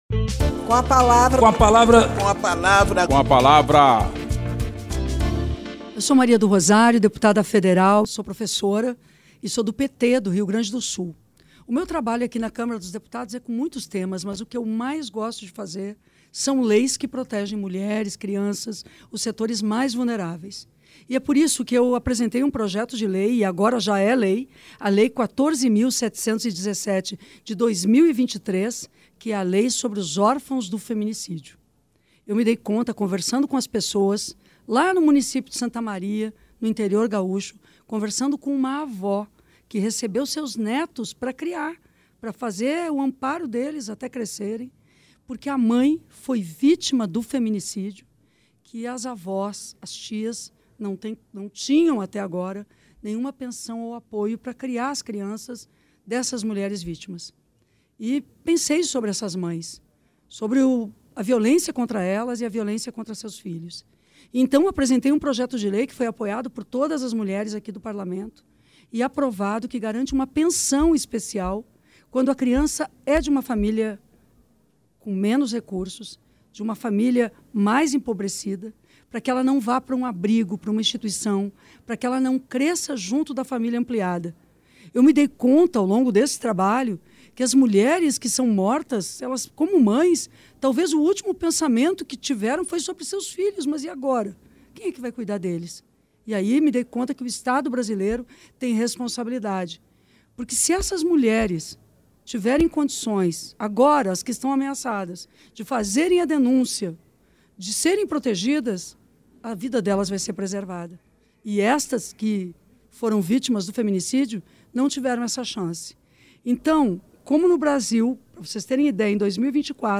A deputada Maria do Rosário (PT-RS) exalta a lei, originada pelo projeto que ela apresentou, que concede pensão especial no valor de um salário mínimo a filhos e dependentes de vítimas de feminicídio.
Espaço aberto para que cada parlamentar apresente aos ouvintes suas propostas legislativas